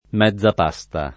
mezzapasta
vai all'elenco alfabetico delle voci ingrandisci il carattere 100% rimpicciolisci il carattere stampa invia tramite posta elettronica codividi su Facebook mezzapasta [ m Hzz ap #S ta ] s. f. (tecn.); pl. mezzepaste — anche mezza pasta [id.]